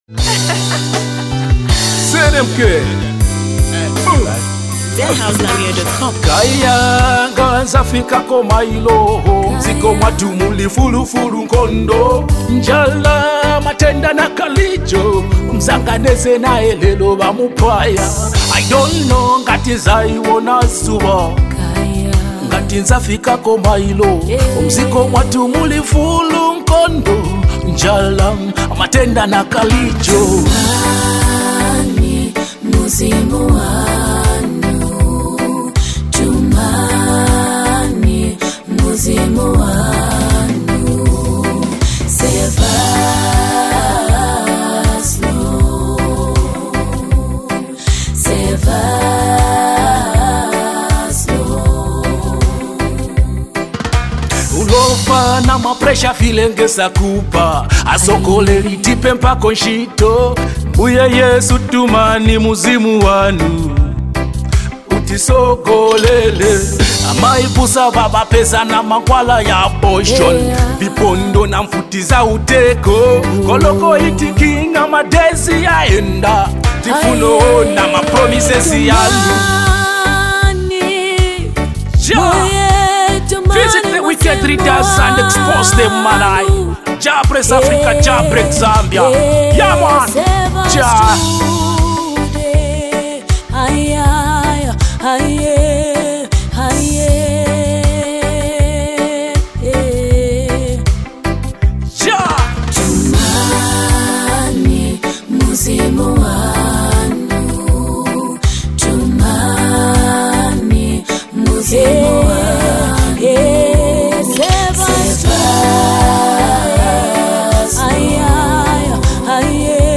soul-stirring gospel collaboration
With heartfelt vocals and uplifting melodies